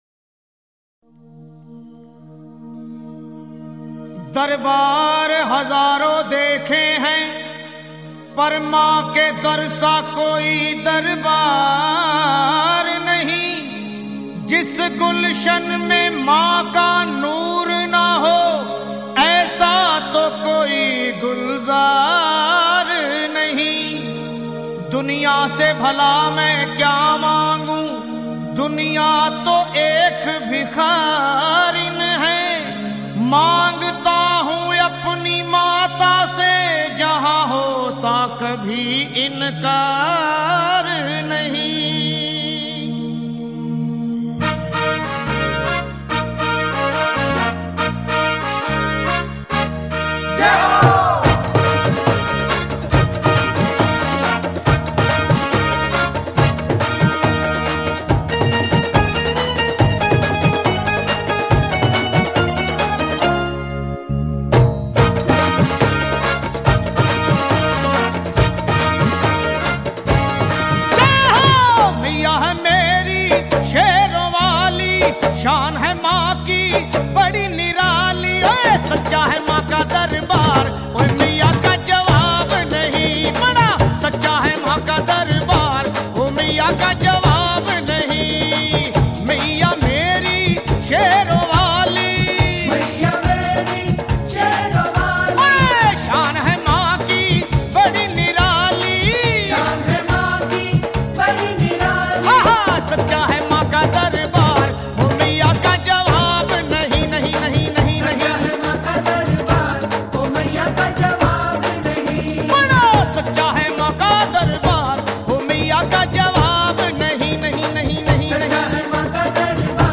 Mata Bhajan
Top Devotional Song
Navratri BHajan